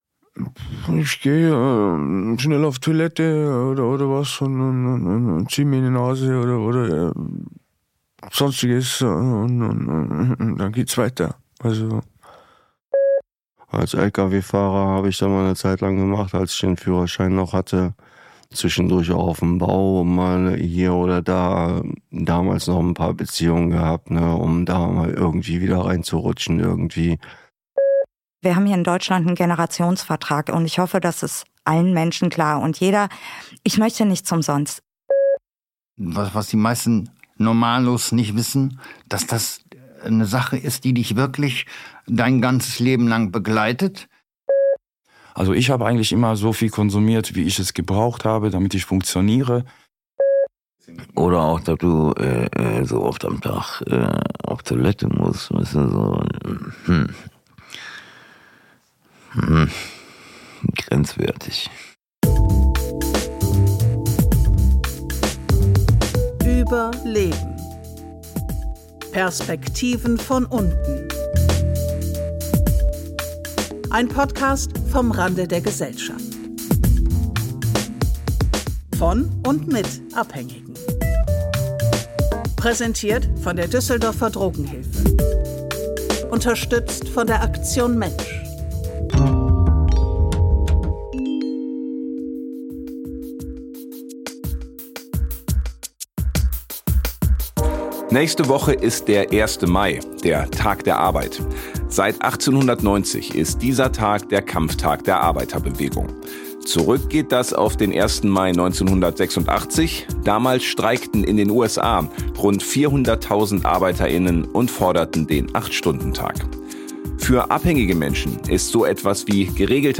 In dieser Episode erzählen Euch drogenabhängige Menschen von ihrem beruflichen Werdegang, von der Problematik mit den Drogen auf der Arbeit und von dem, was sie noch so vorhaben.